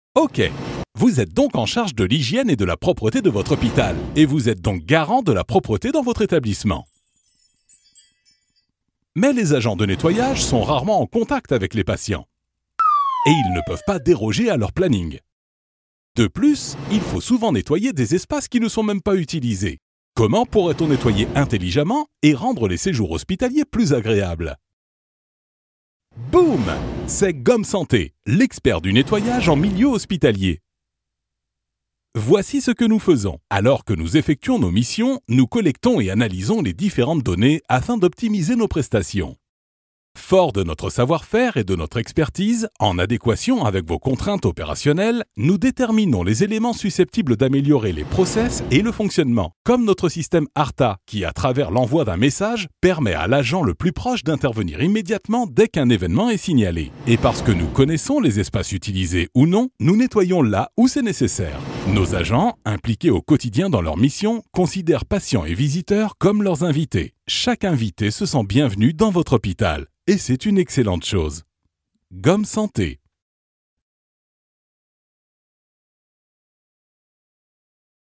Voix homme medium grave pour Pub Jingle Institutionnel
Sprechprobe: Industrie (Muttersprache):